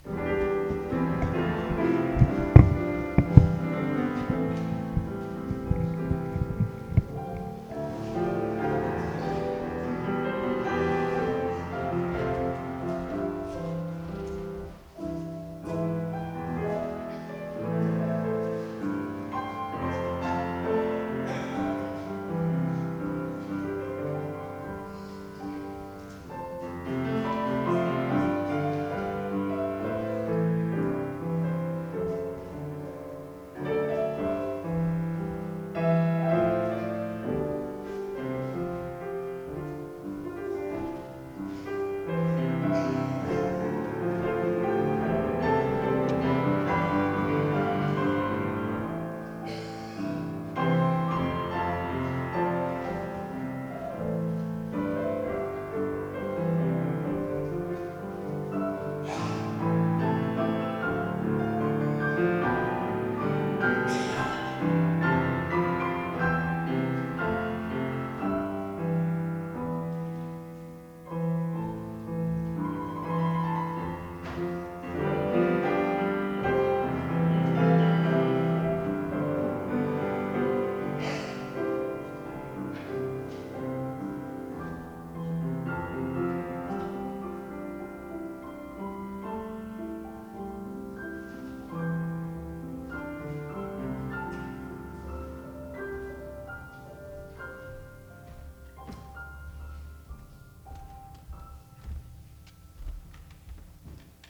[Fuusm-l] Some music from past services